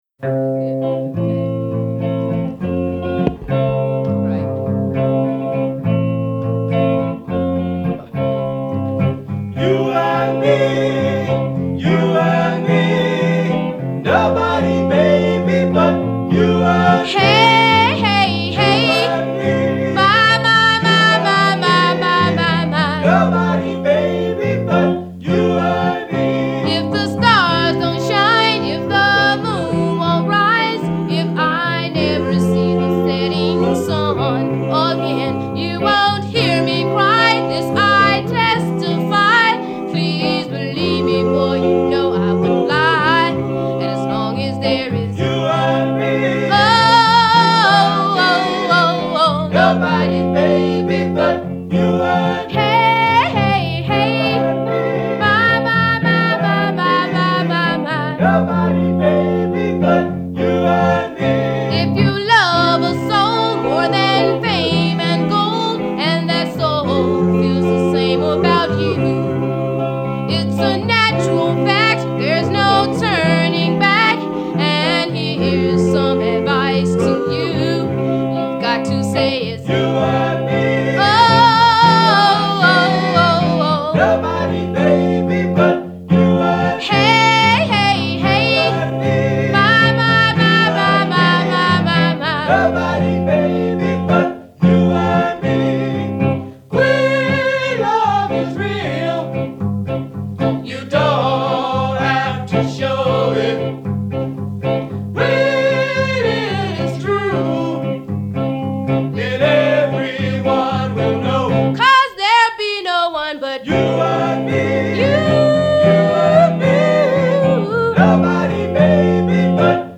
a discovered R&B group
cut a demo in the early 70s
such a pretty and romantic song